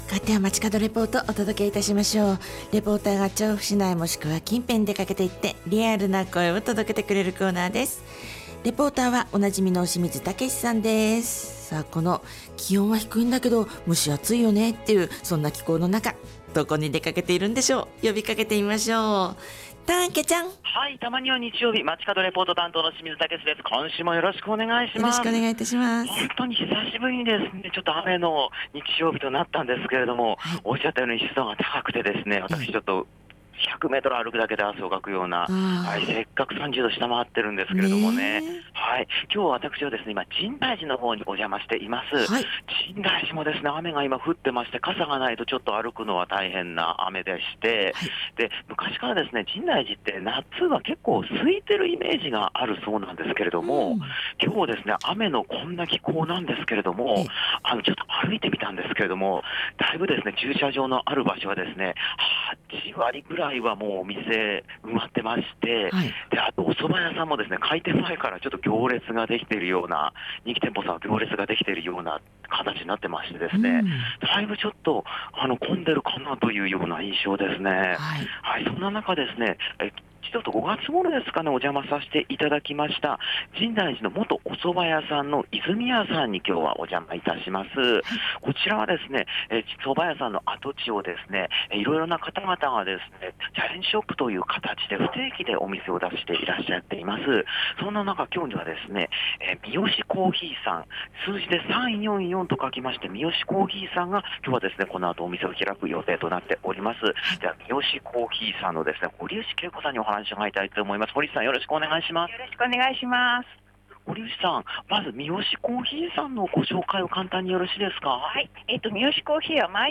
曇り空の下からお届けした本日の街角レポートは、
皆さんにはひと言ずついただいているほか、放送の中では1曲さわりだけご披露いただきました。